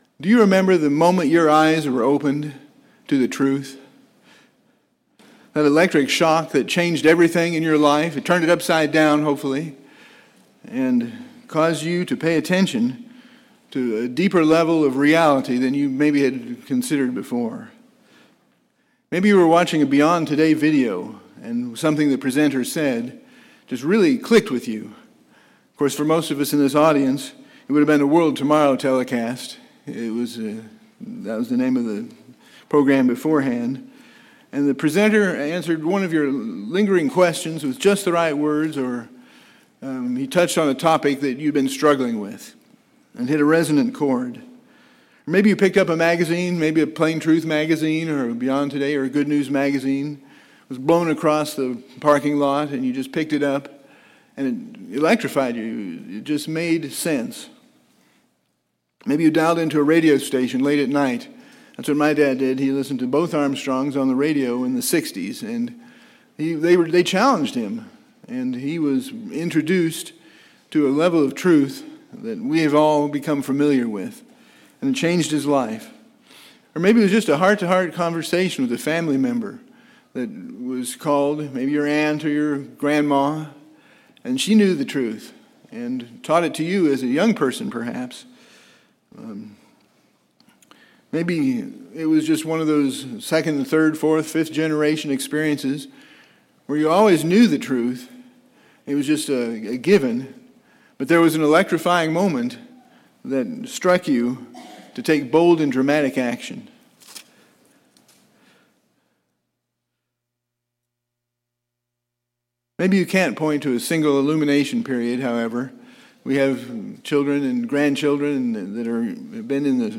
Eighth Day sermon Studying the bible?